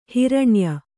♪ hiraṇya